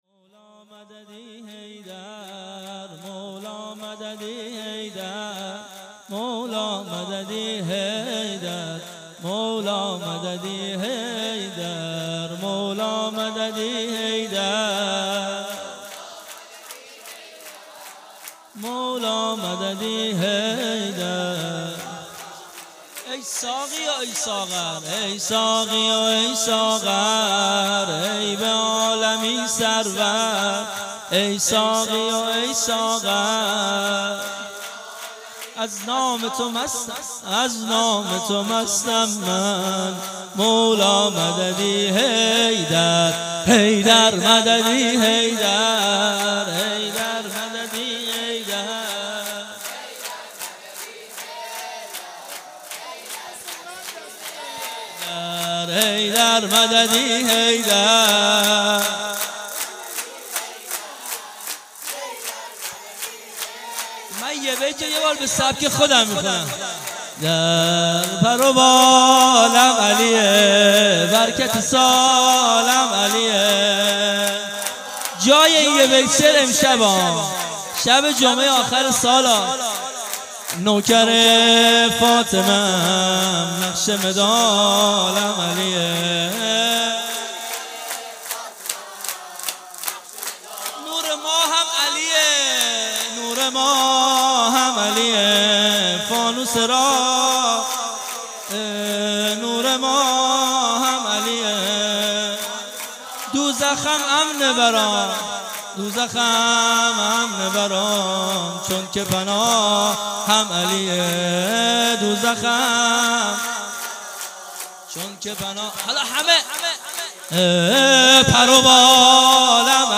عاشقان اهل بیت - هفتگی 23 اسفند- سرود